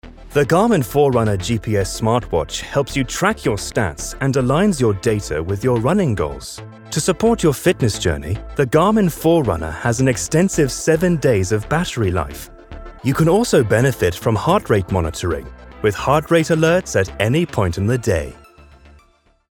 Anglais (Britannique)
Profonde, Naturelle, Polyvalente, Fiable, Amicale
Vidéo explicative
Les clients ont décrit sa voix comme riche, résonnante, profonde, teintée de gravitas, très adaptable à différents genres et (presque) incontestablement britannique !